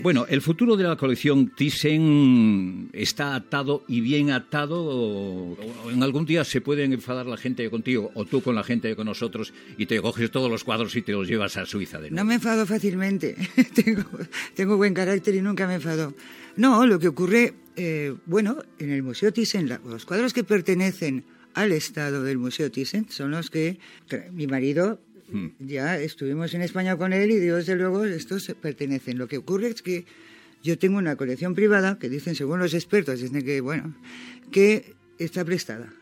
Fragment d'una entrevista a la baronessa Camen "Tita" Cervera sobre la colecció d'art Thyssen.
Info-entreteniment